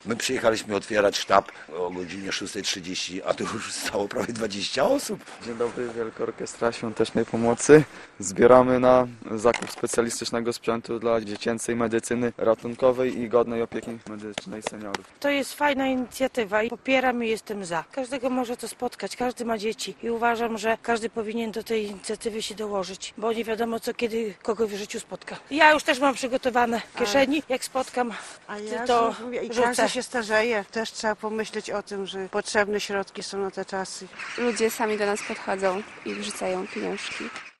WOŚP gra w Łomży - relacja